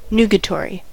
nugatory: Wikimedia Commons US English Pronunciations
En-us-nugatory.WAV